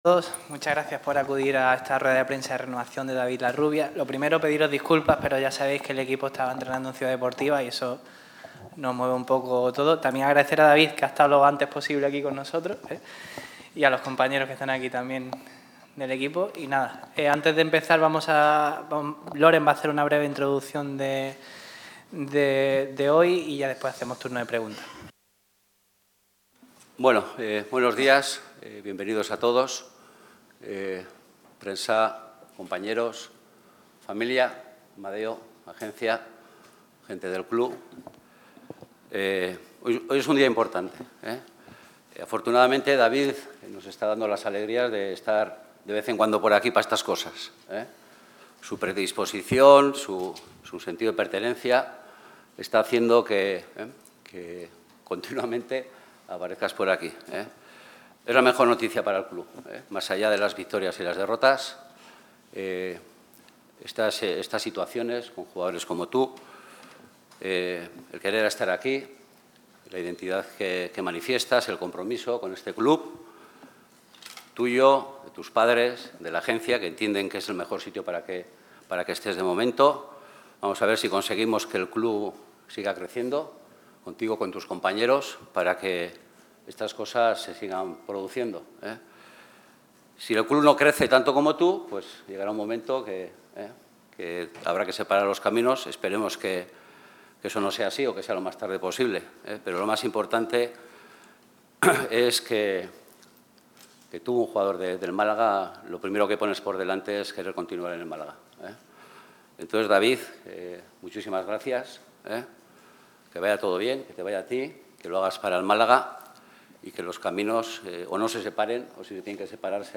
David Larrubia ha comparecido ante los medios de comunicación en la presentación de la renovación de su vínculo con la entidad hasta 2028.